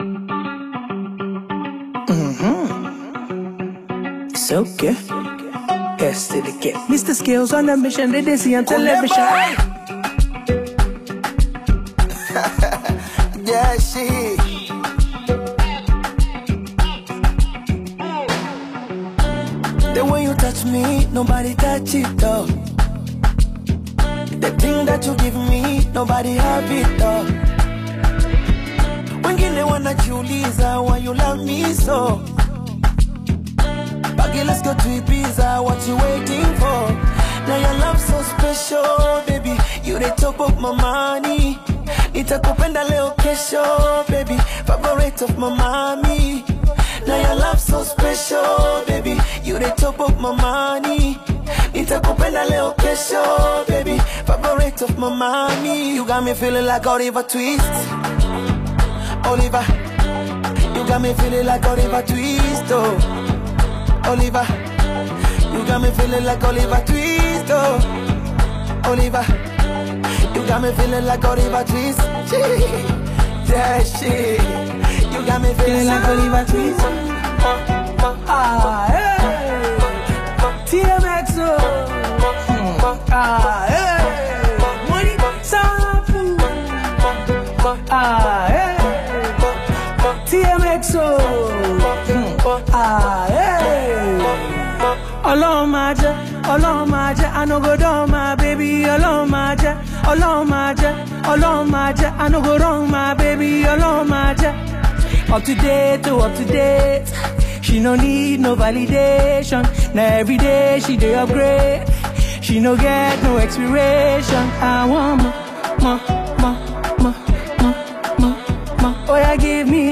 African Music You may also like